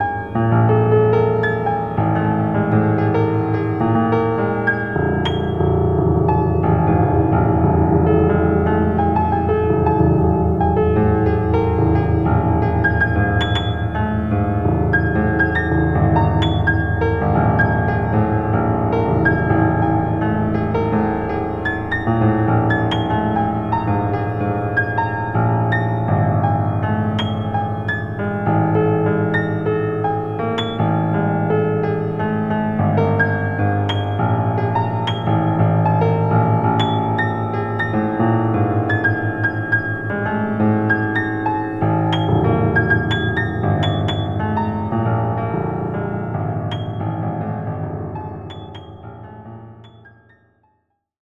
random-frequency-raw-2-all-piano-folder-edit.mp3